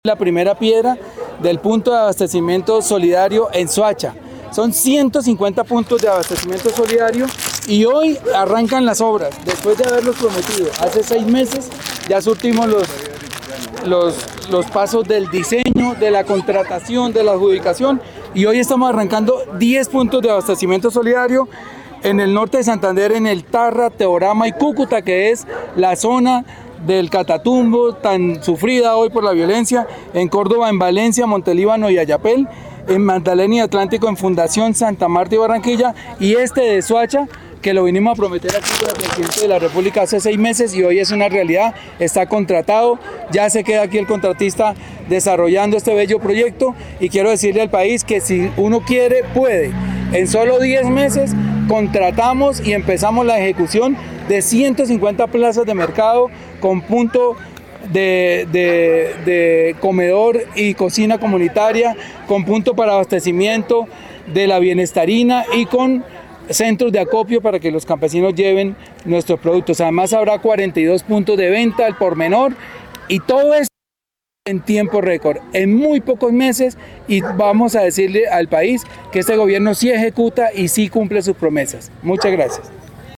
En el acto simbólico de colocación de la primera piedra, Bolívar dijo: “Son 150 de estos PAS que construiremos en el país; más de medio billón de pesos se invirtieron para llevar soluciones contra el hambre para más de 7 millones de personas en Colombia”.
director Gustavo Bolívar